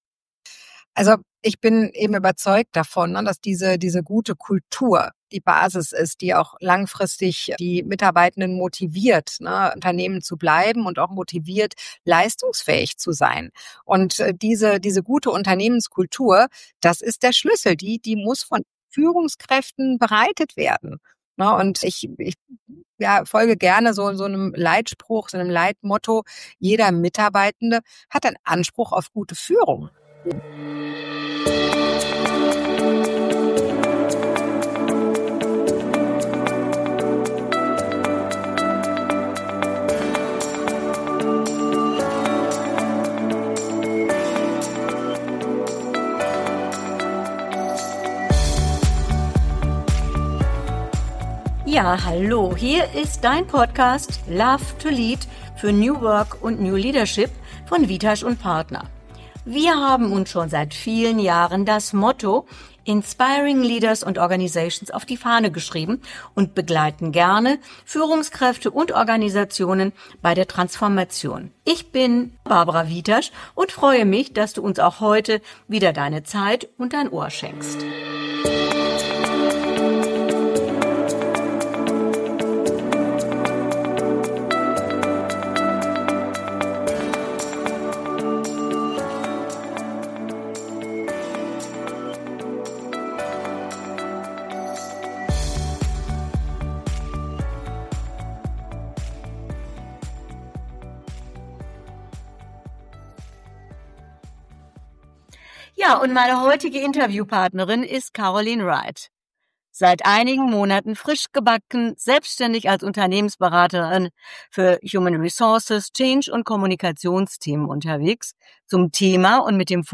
Ein Gespräch voller Klartext, Herz und Humor – für alle, die Leadership neu denken (und leben) wollen.